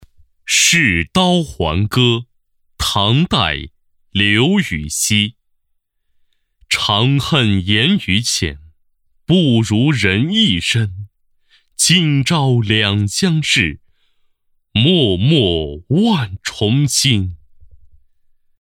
视刀环歌-音频朗读